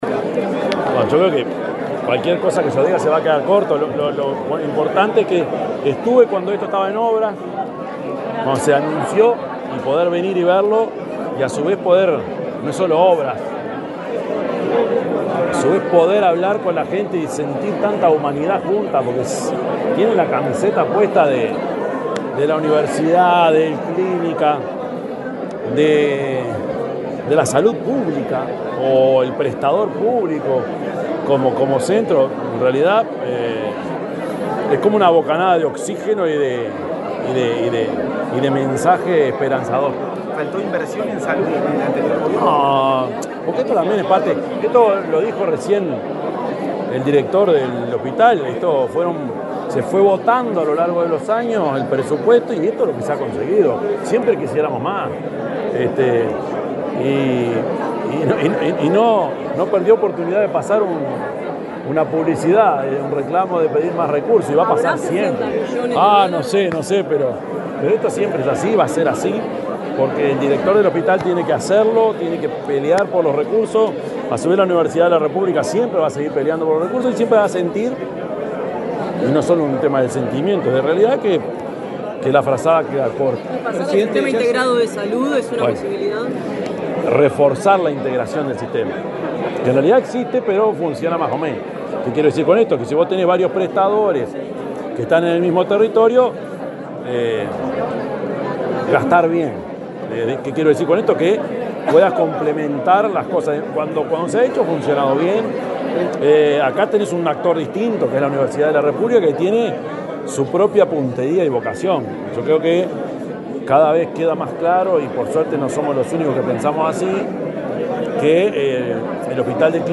Declaraciones del presidente de la República, Yamandú Orsi
Declaraciones del presidente de la República, Yamandú Orsi 07/05/2025 Compartir Facebook X Copiar enlace WhatsApp LinkedIn El presidente de la República, profesor Yamandú Orsi, dialogó con la prensa, luego de asistir a la inauguración del centro de diálisis del Hospital de Clínicas.